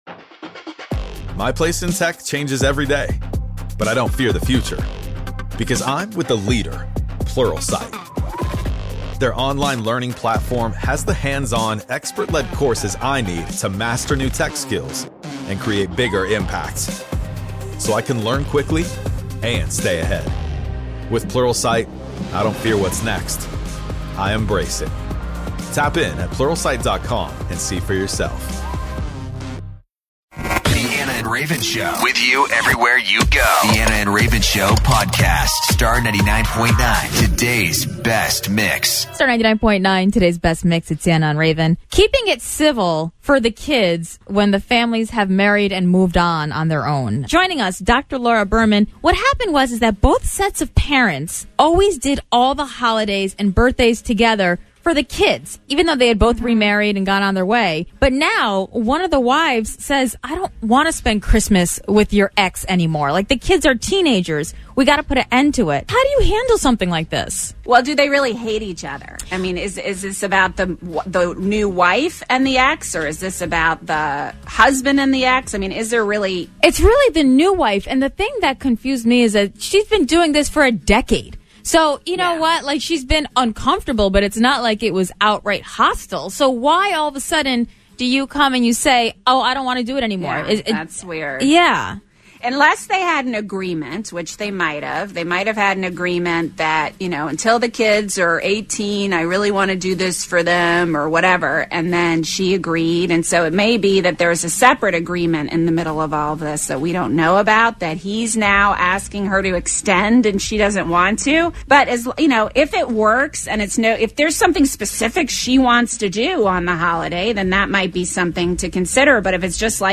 Dr. Laura Berman joins us in studio today to talk about today’s couples court issue and how they should handle a situation such as this.